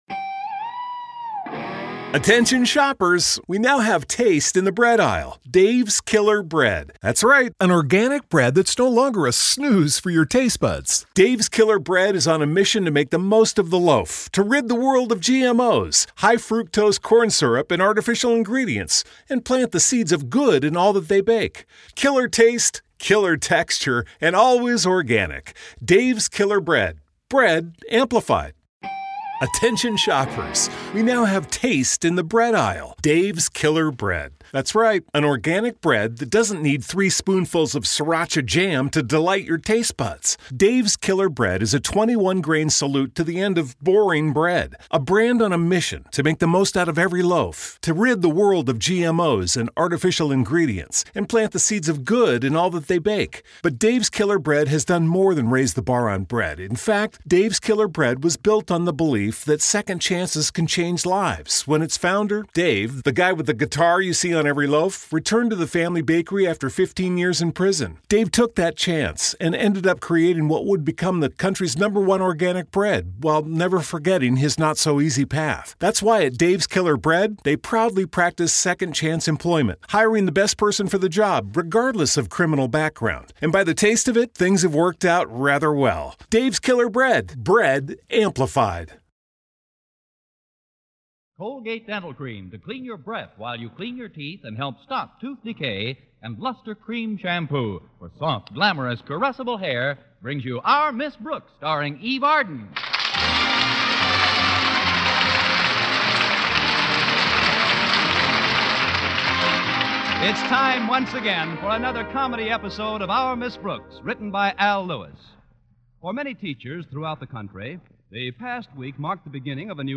Our Miss Brooks was a beloved American sitcom that ran on CBS radio from 1948 to 1957.
The show starred the iconic Eve Arden as Connie Brooks, a wisecracking and sarcastic English teacher at Madison High School. Arden's portrayal of Miss Brooks was both hilarious and endearing, and she won over audiences with her quick wit and sharp one-liners The supporting cast of Our Miss Brooks was equally memorable. Gale Gordon played the uptight and pompous Principal Osgood Conklin, Richard Crenna played the dimwitted but lovable student Walter Denton, and Jane Morgan played Miss Brooks' scatterbrained landlady, Mrs. Davis.